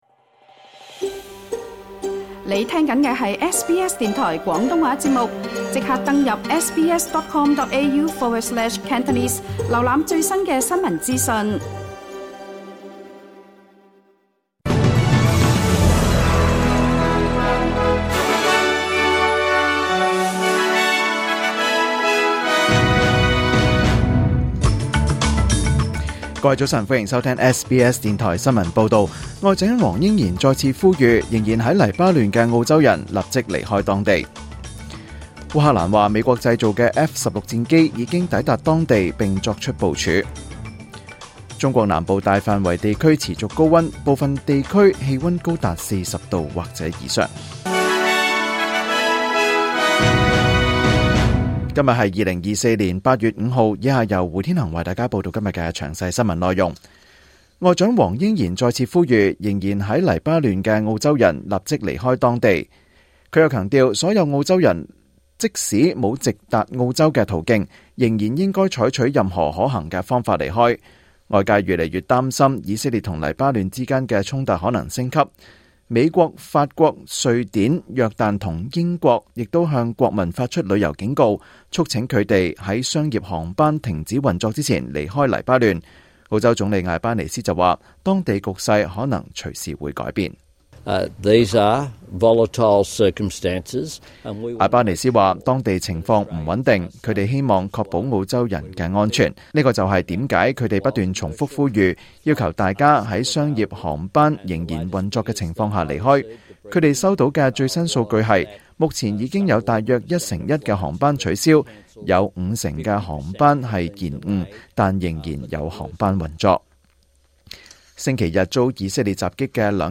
2024年8月5日SBS廣東話節目詳盡早晨新聞報道。